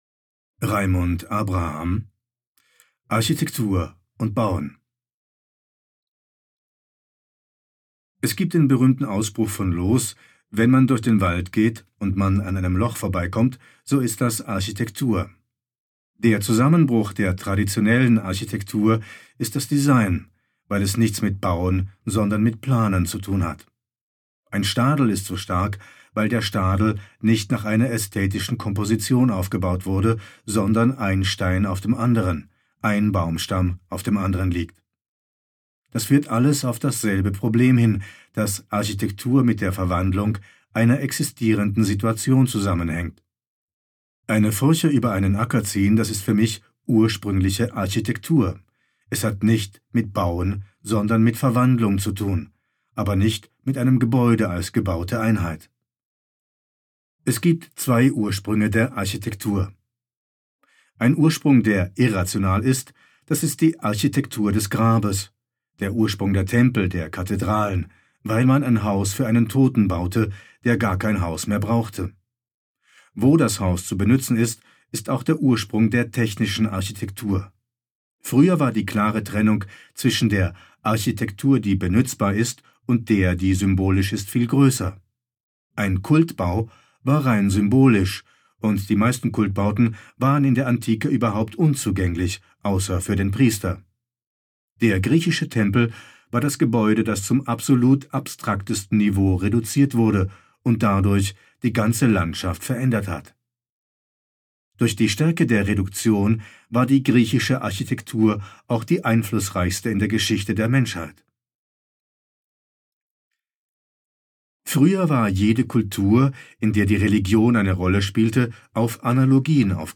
Das anlässlich der Eröffnung von aut im Adambräu erscheinende Lesebuch „reprint“ war die Basis für 11 Hörstationen in der 2005 gezeigten Eröffnungsausstellung vermessungen.